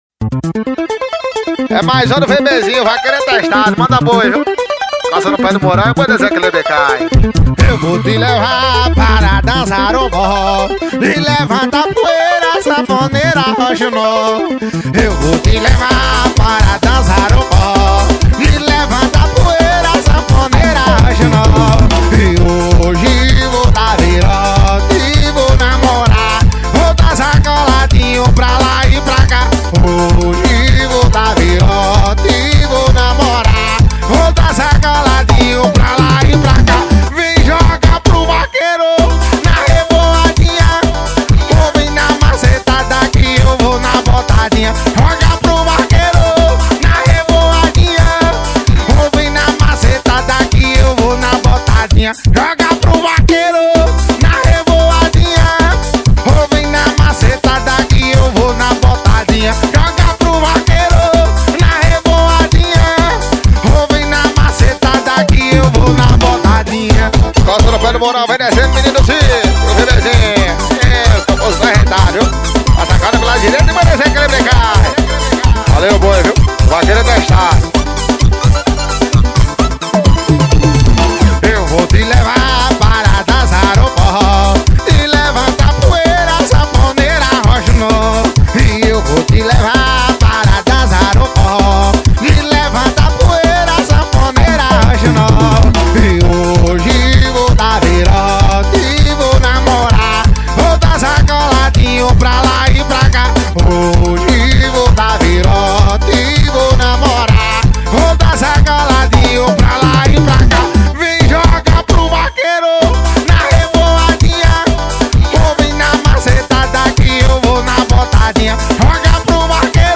2024-06-17 14:21:51 Gênero: Forró Views